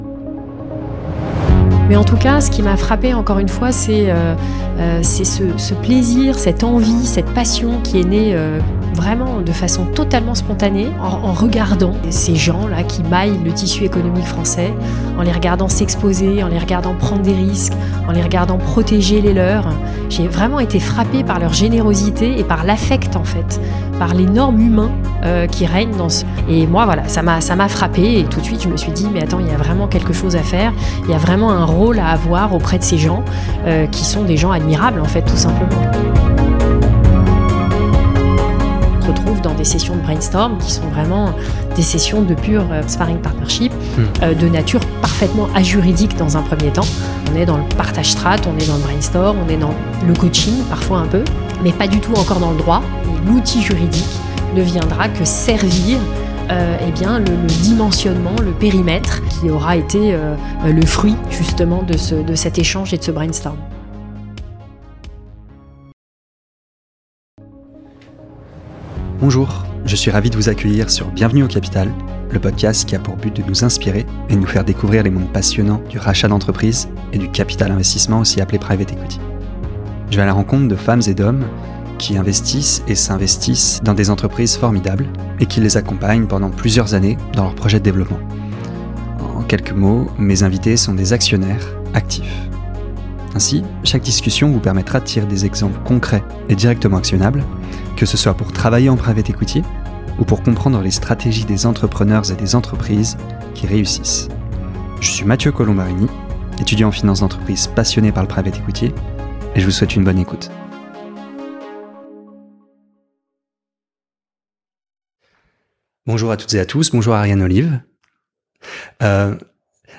qui revient avec une énergie communicative sur son expérience en Private Equity !